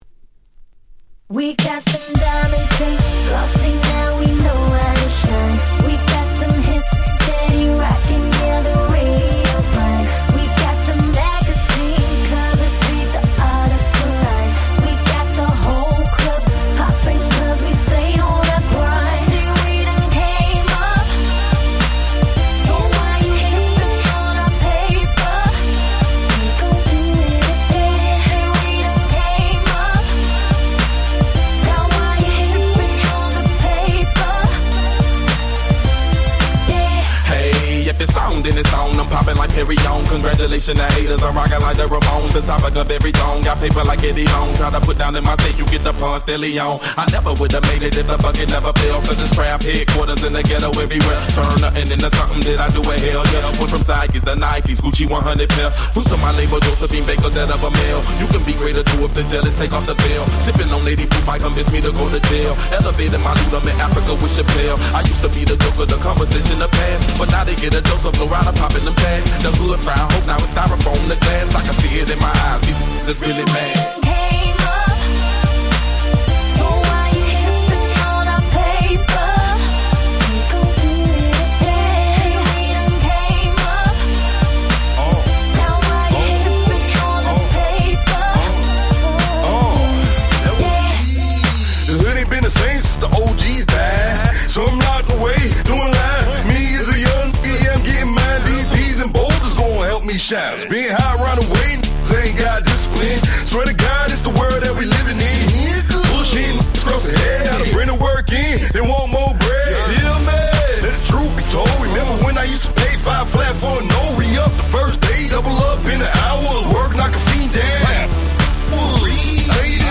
フローライダ デヴィットゲッタ EDM アゲアゲ パリピ 10's